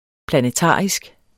Udtale [ planəˈtɑˀisg ]